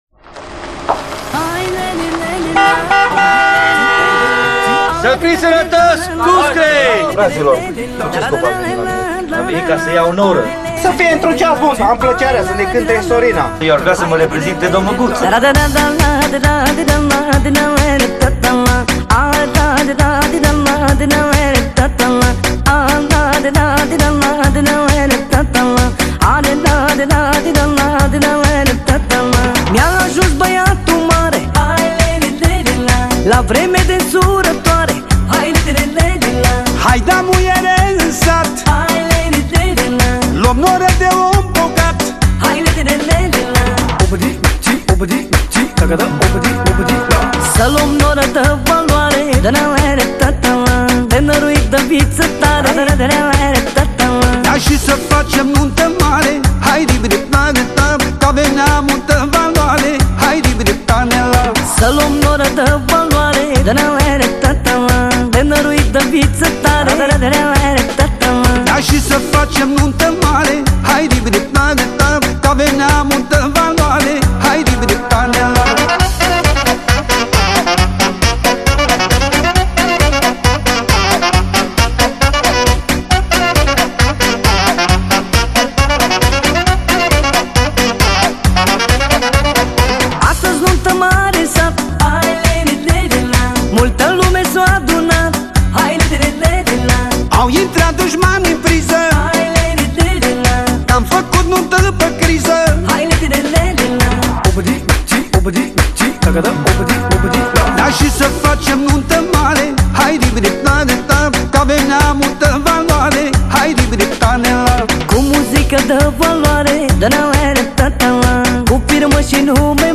Главная » Файлы » Музыка » Танцевальная/Послушать